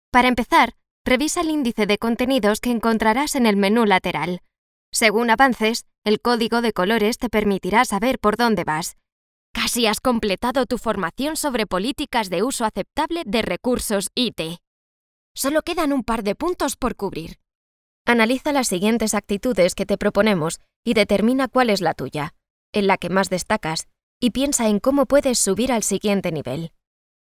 Young, Urban, Cool, Reliable, Natural
E-learning